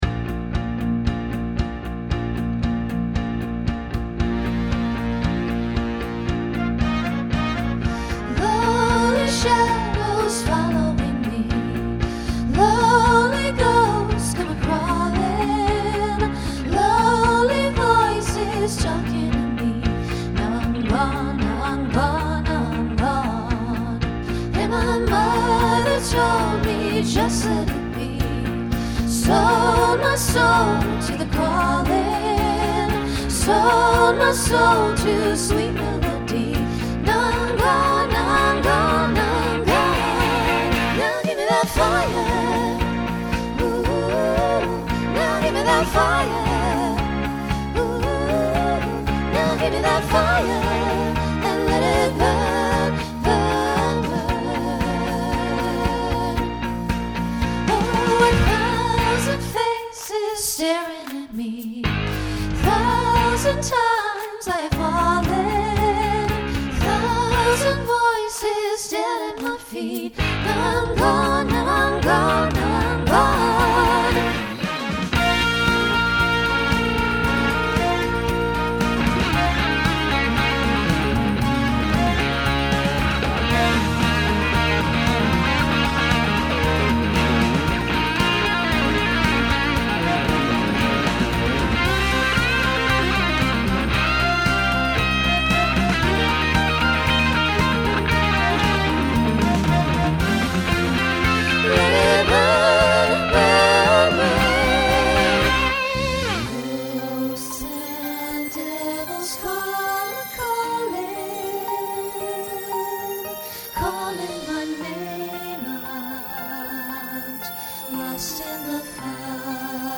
Genre Folk , Rock
Mid-tempo , Transition Voicing SSA